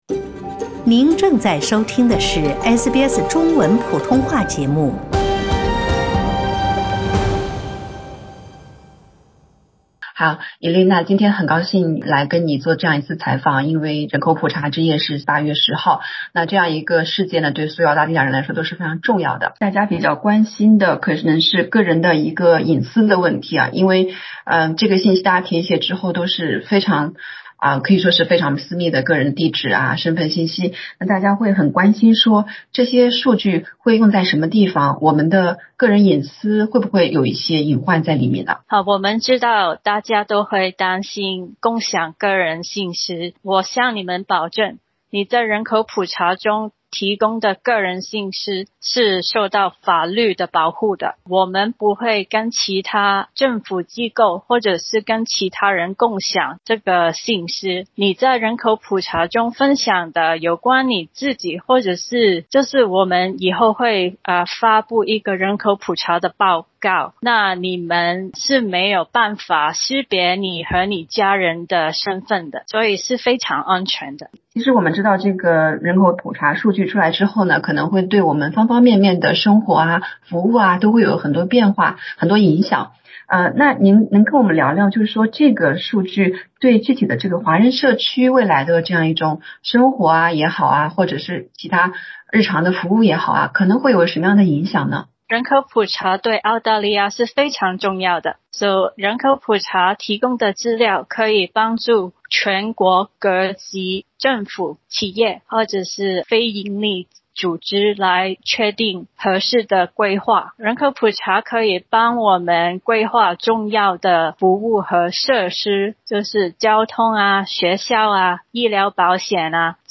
（請聽寀訪） 澳大利亞人必鬚與他人保持至少 1.5 米的社交距離，請查看您所在州或領地的最新社交限制措施 。